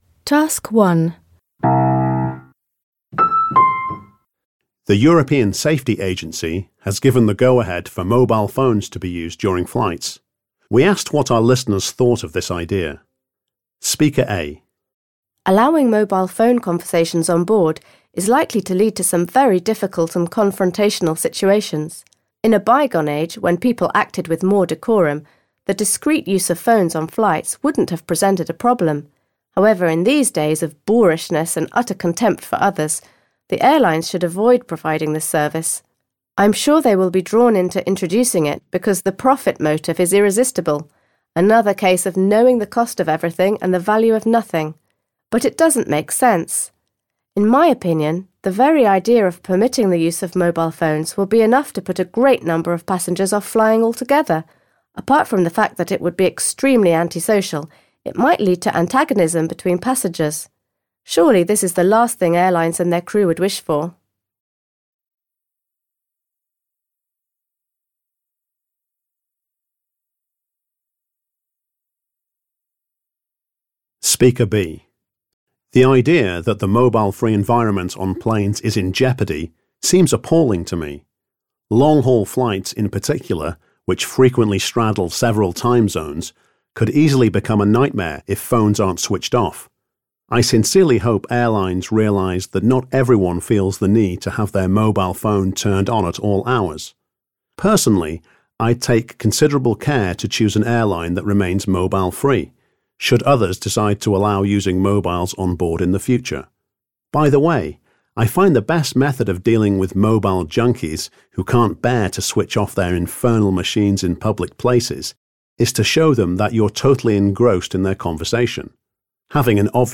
You are going to hear three people talking about the use of mobile phones on planes.